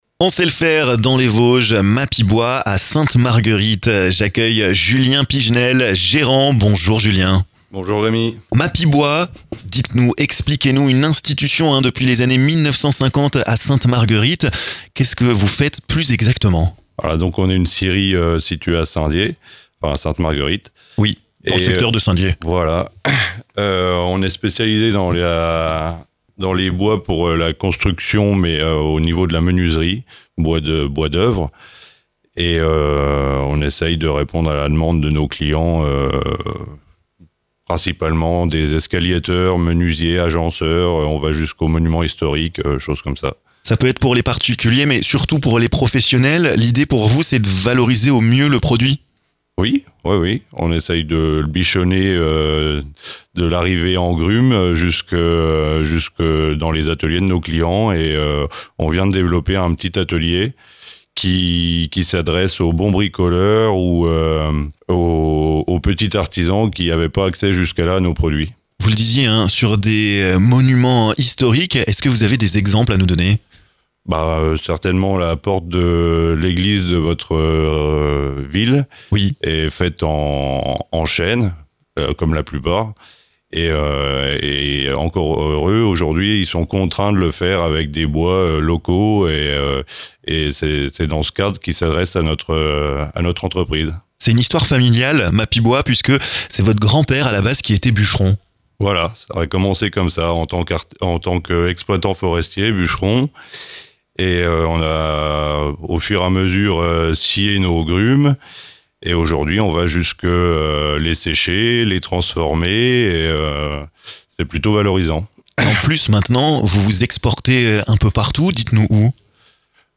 est passé dans nos studios! Il vous présente le savoir-faire de son entreprise dans ce podcast!